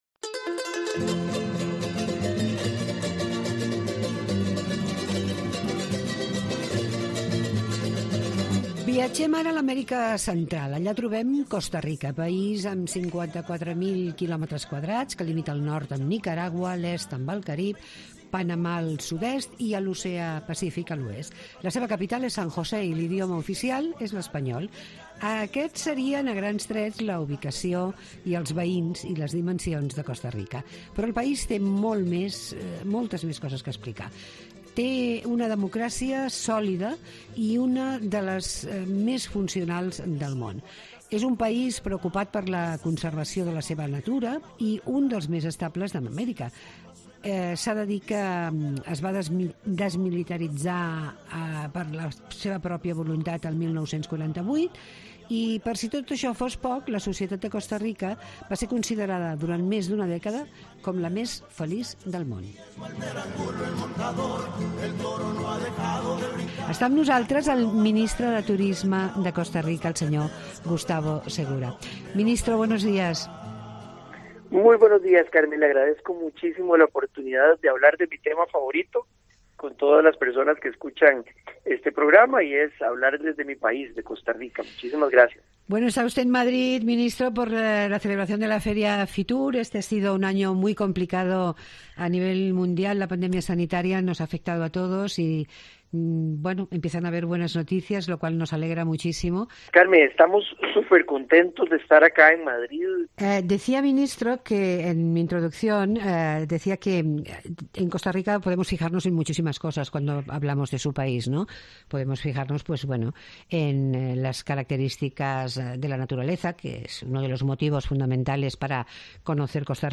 El Ministro de Turismo de Costa Rica en Tira milles